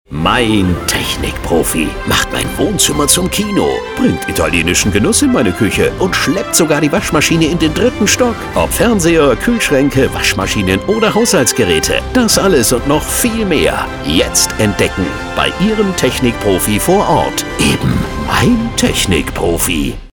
werbespot.mp3